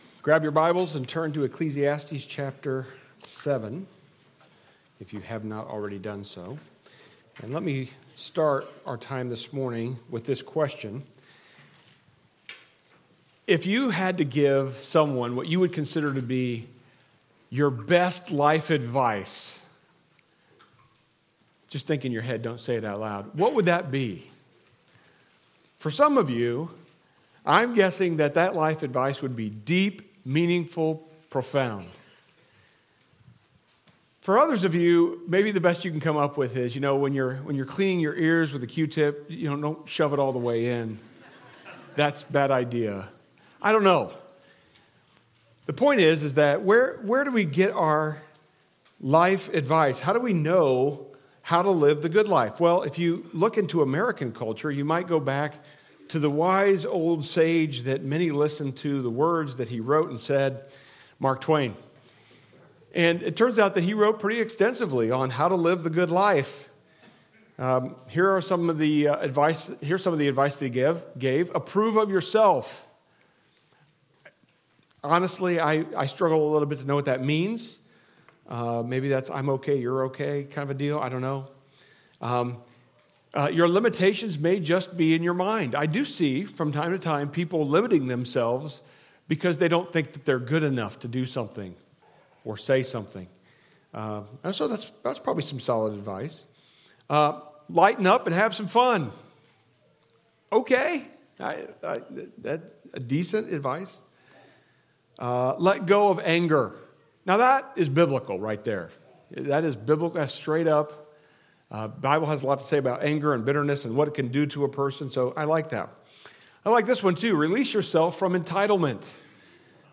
Sermons Archive - Page 28 of 52 - Delaware Bible Church